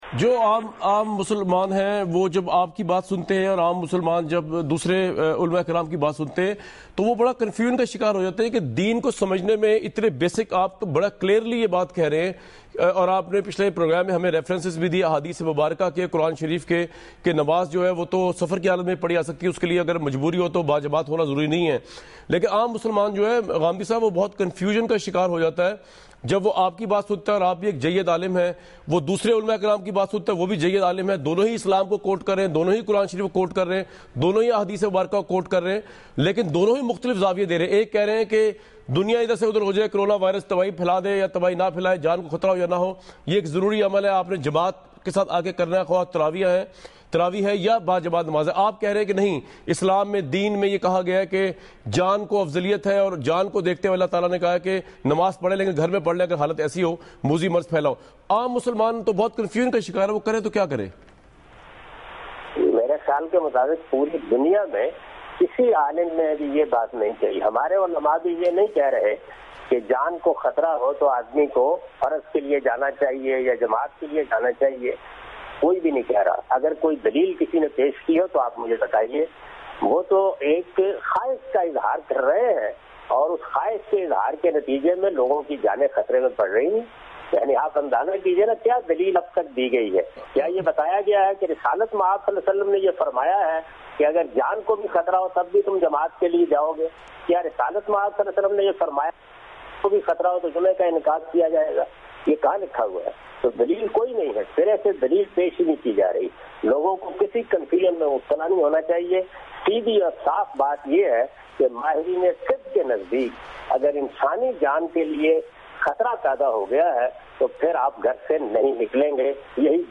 Category: TV Programs / Dunya News / Questions_Answers /
دنیا ٹی وی کے اس پروگرام میں جناب جاوید احمد صاحب غامدی "نماز تراویح کی مسجد میں ادائیگی پر علما کا اختلاف: عام آدمی کیا کرے؟" سے متعلق سوال کا جواب دے رہے ہیں۔